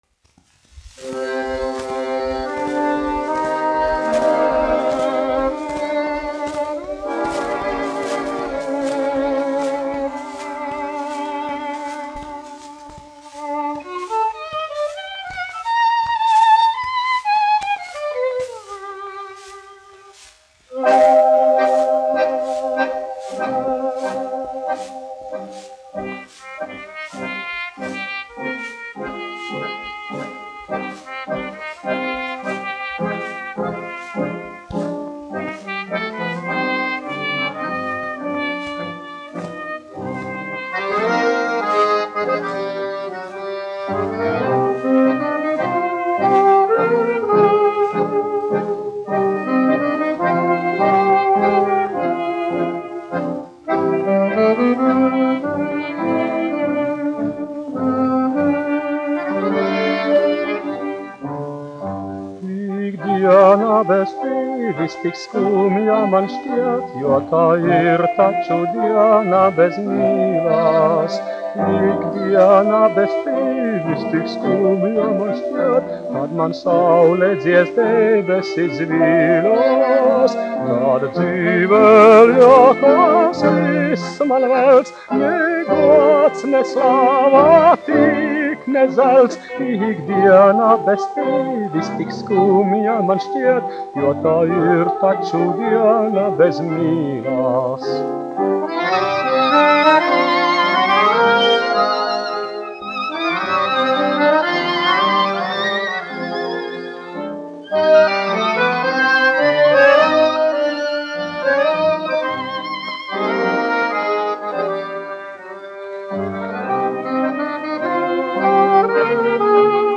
1 skpl. : analogs, 78 apgr/min, mono ; 25 cm
Kinomūzika